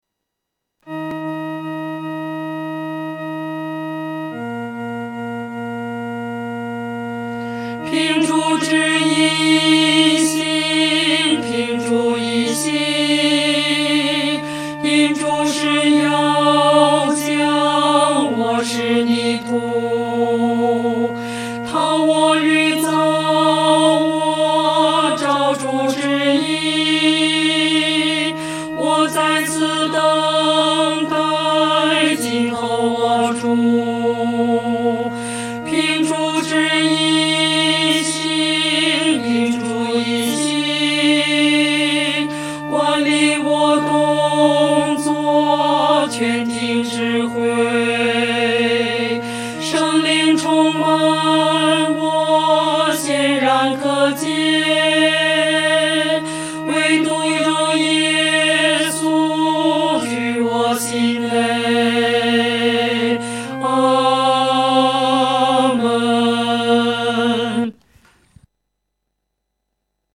合唱
女高 下载